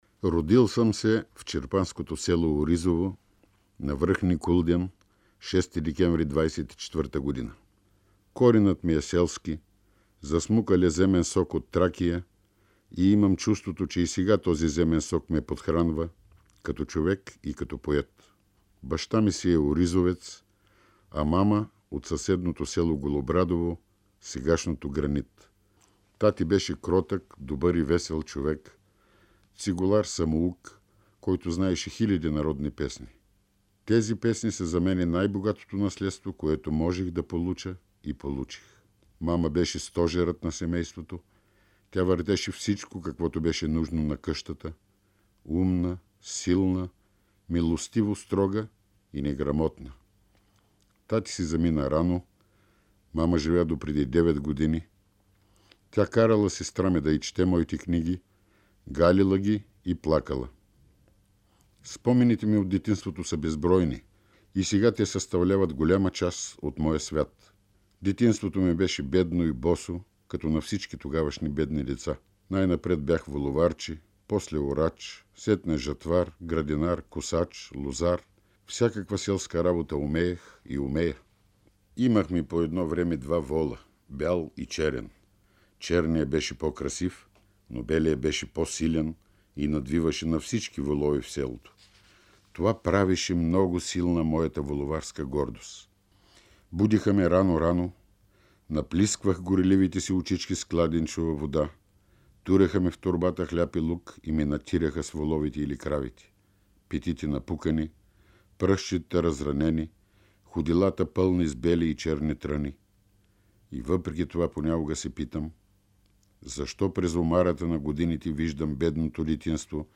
В навечерието на своята 60-годишнина Павел Матев се връща към спомените за родния край, родителите си и детските години, запис 1984 година, Златен фонд на БНР: